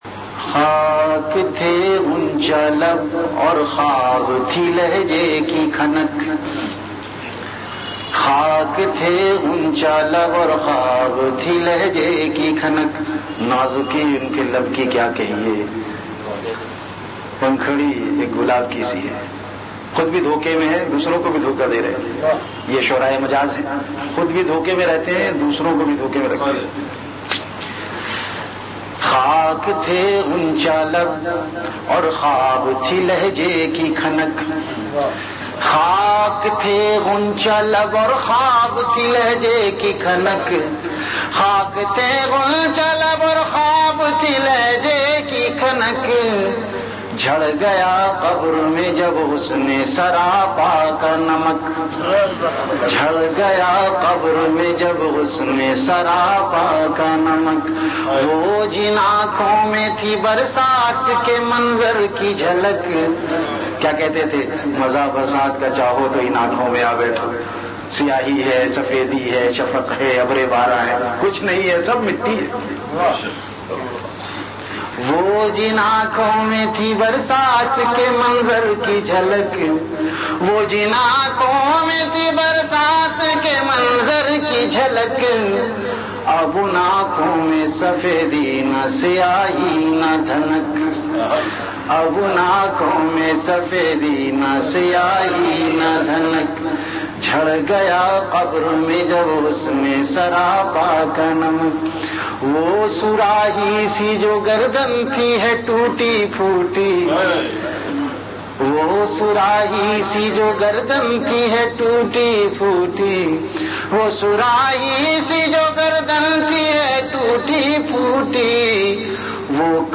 Ashaar · Khanqah Imdadia Ashrafia
CategoryAshaar
VenueKhanqah Imdadia Ashrafia
Event / TimeAfter Isha Prayer